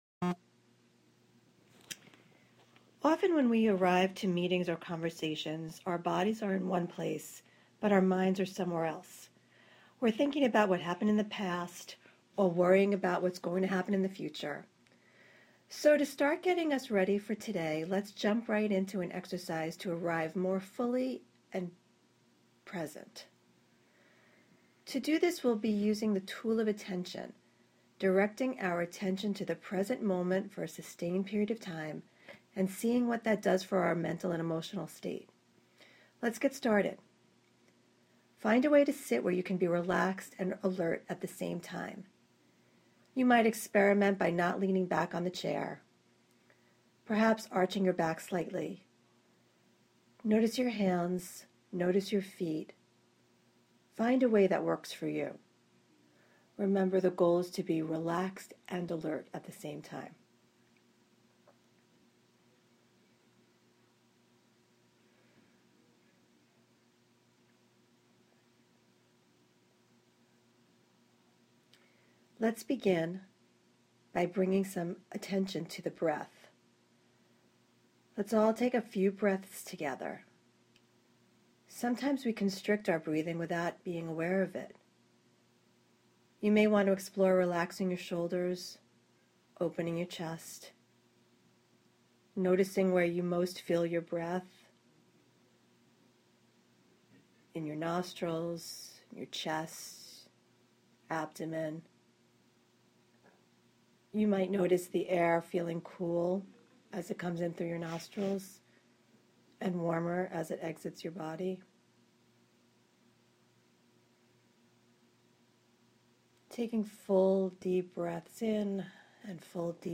Audio Meditations